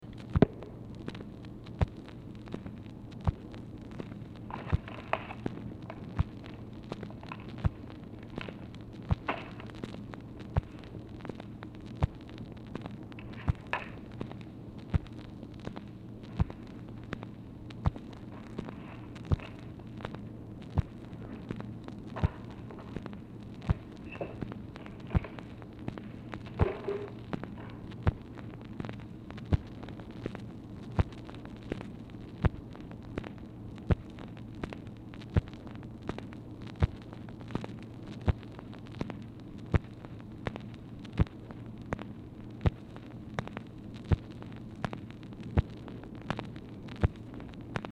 Telephone conversation # 2865, sound recording, OFFICE NOISE, 4/6/1964, time unknown | Discover LBJ
Format Dictation belt
Location Of Speaker 1 Oval Office or unknown location